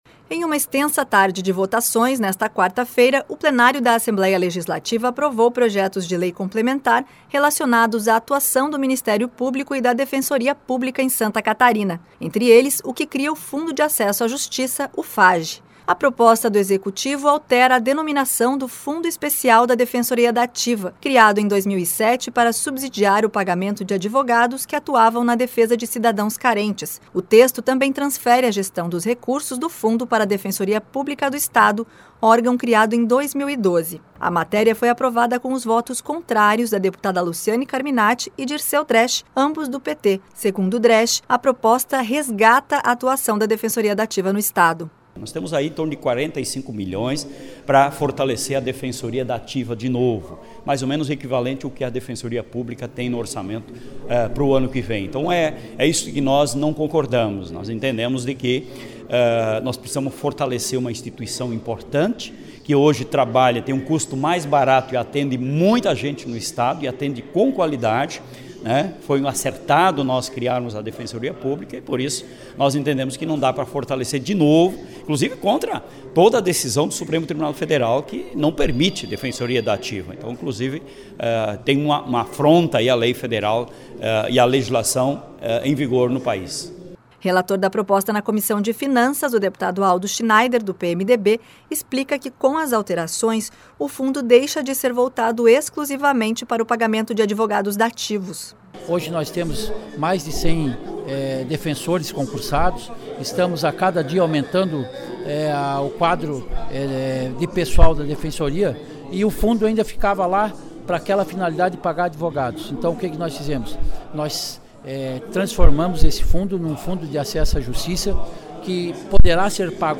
Entrevistas com:
- deputado Dirceu Dresch (PT)
- deputado Aldo Schneider (PMDB)